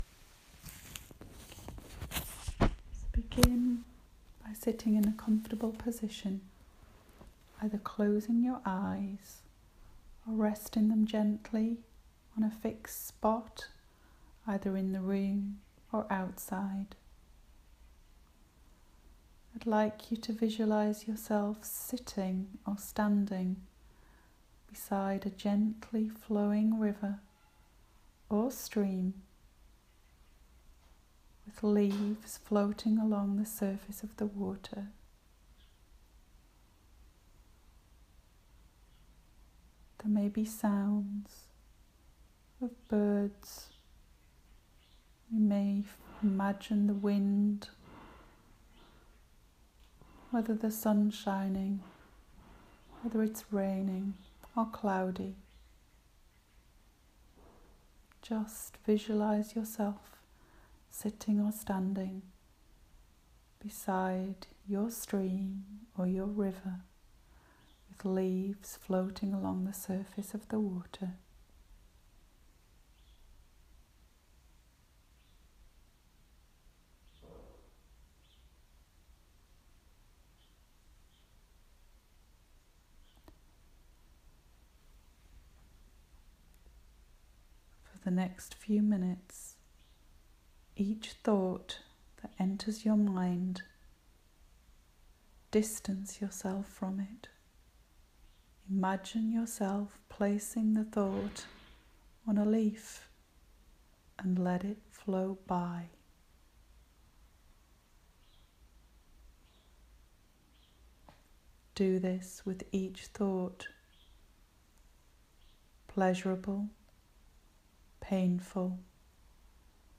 Leaves on a stream relaxation session